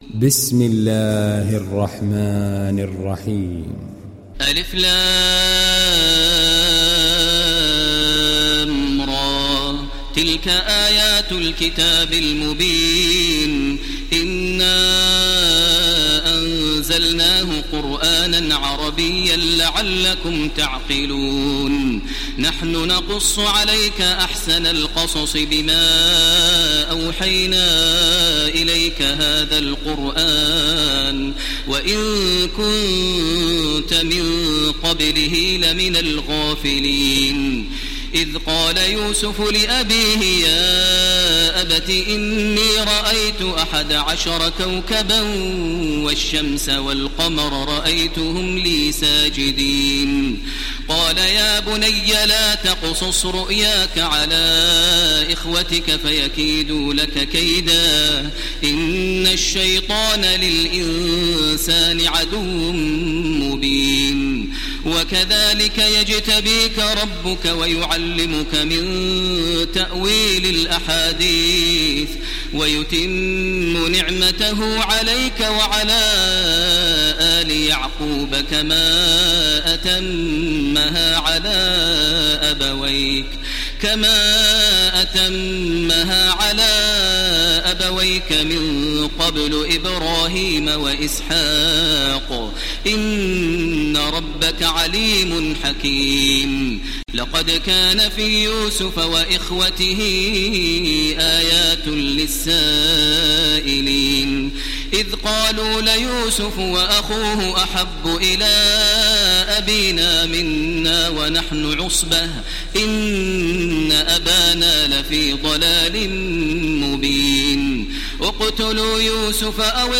Télécharger Sourate Yusuf Taraweeh Makkah 1430
Moratal